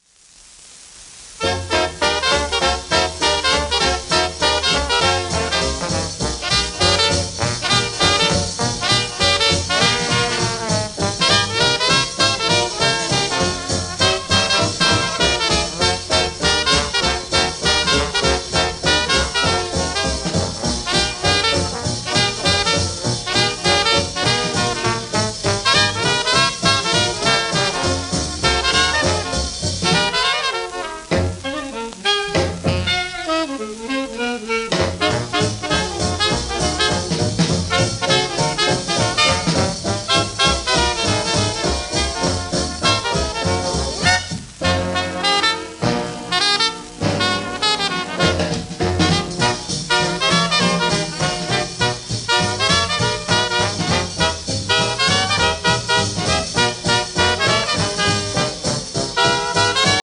1940年代の録音。